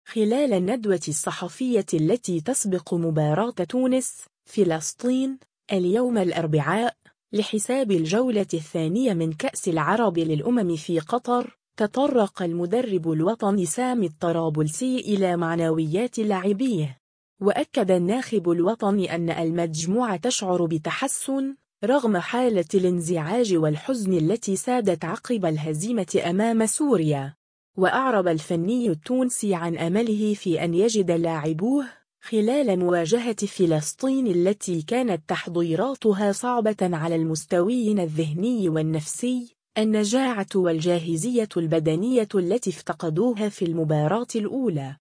خلال الندوة الصحفية التي تسبق مباراة تونس – فلسطين، اليوم الأربعاء، لحساب الجولة الثانية من كأس العرب للأمم في قطر، تطرّق المدرّب الوطني سامي الطرابلسي إلى معنويات لاعبيه.